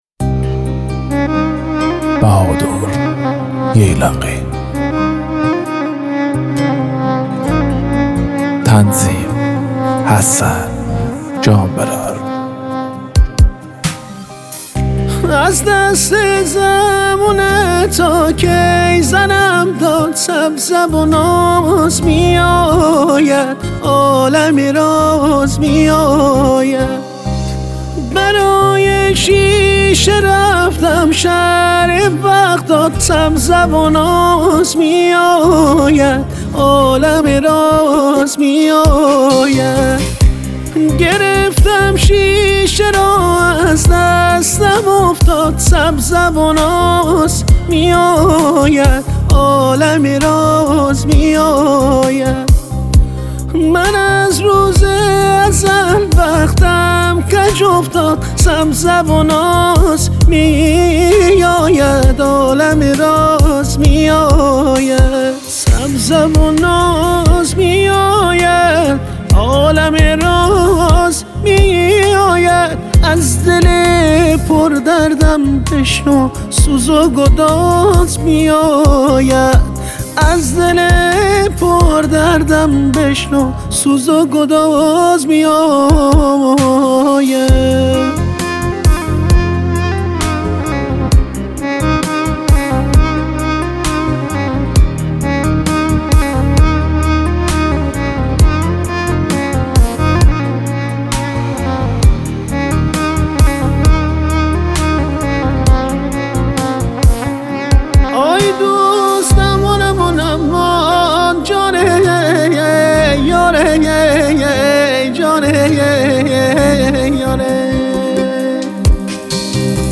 با سبک غمگین مازندرانی